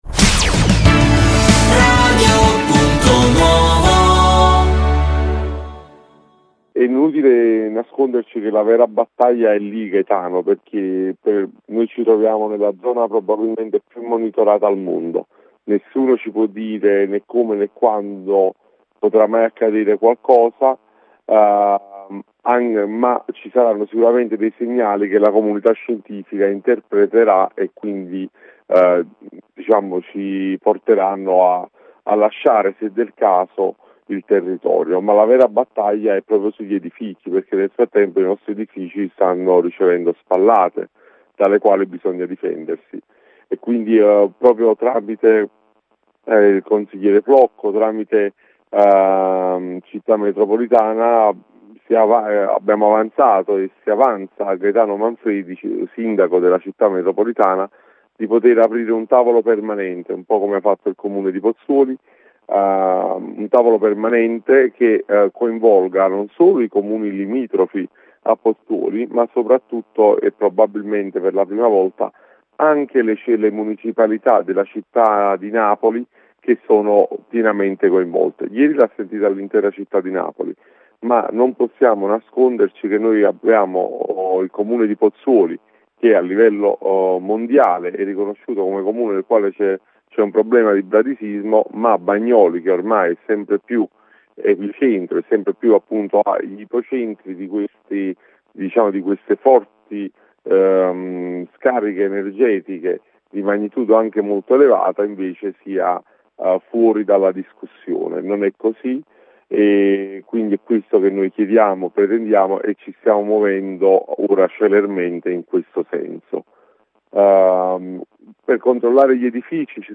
Sulla questione è intervenuto il vice presidente della nona municipalità Sergio Lomasto: